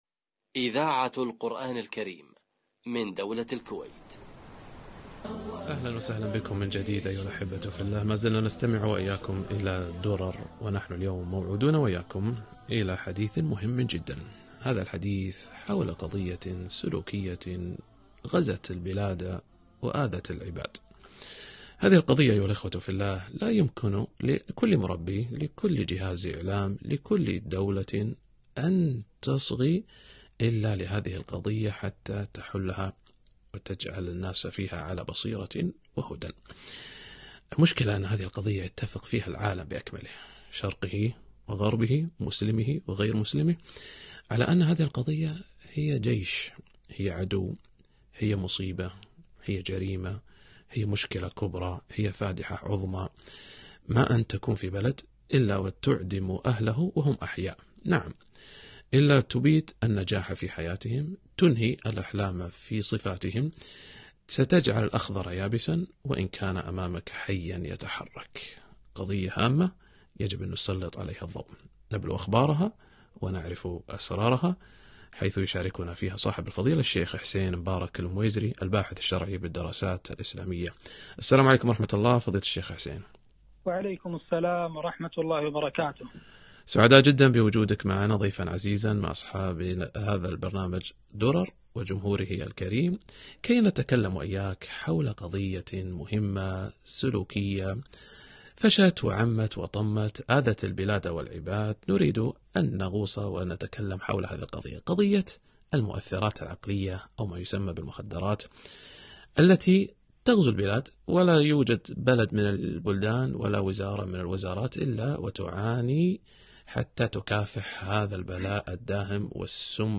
المخدرات وأثرها على الفرد والمجتمع - لقاء إذاعي